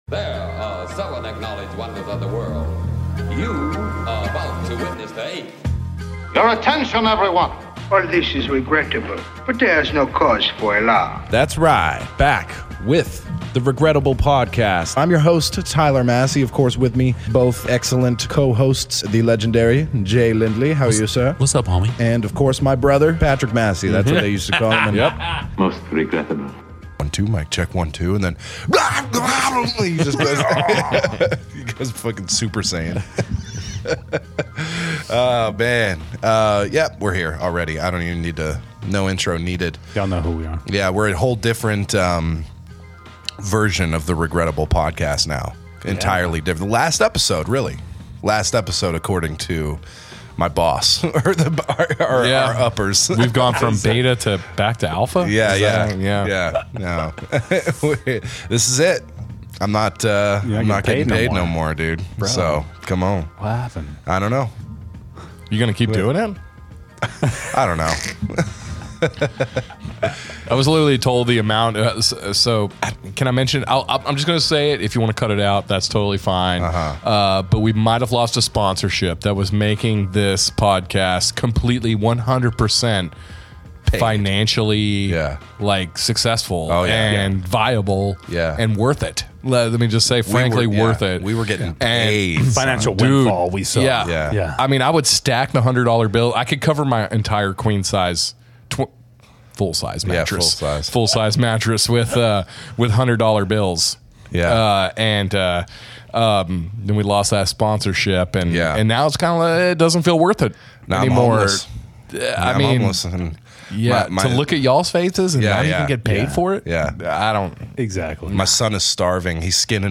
Disclaimer: Strong language, Sensitive subjects.